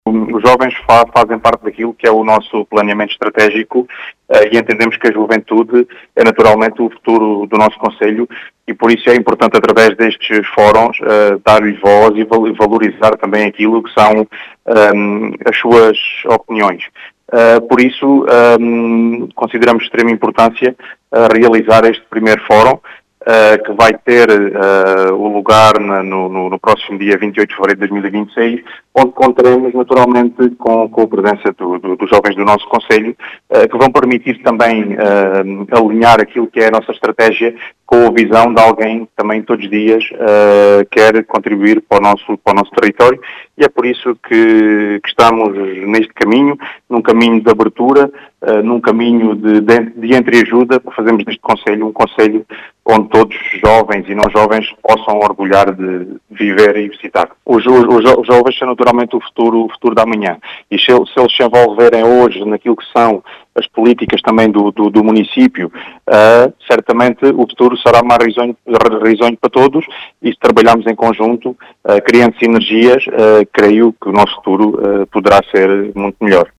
Em declarações à Rádio Vidigueira Ricardo Bonito, presidente da Câmara Municipal de Vidigueira afirma que os jovens “fazem parte do planeamento estratégico” deste  executivo para o futuro do concelho.